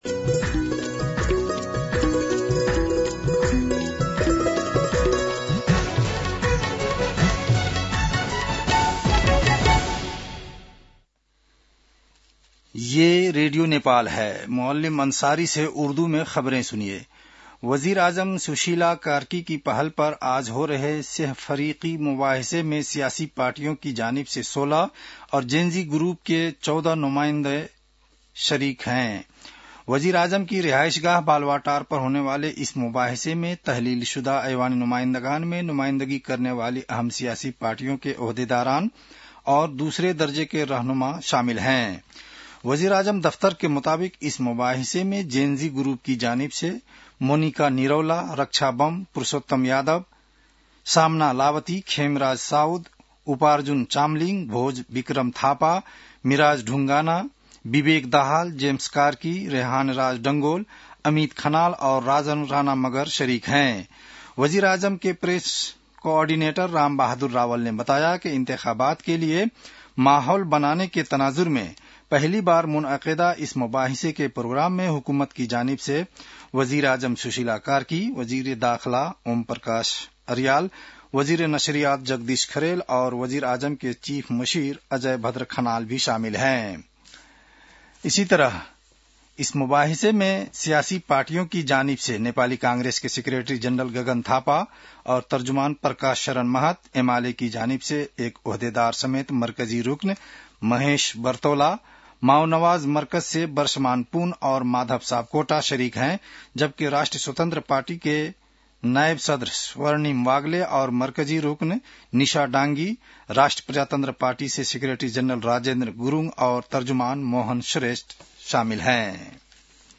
उर्दु भाषामा समाचार : १२ कार्तिक , २०८२